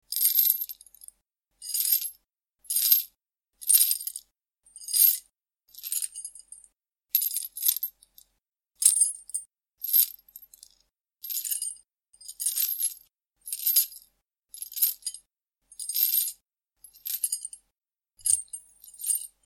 Download Chain sound effect for free.
Chain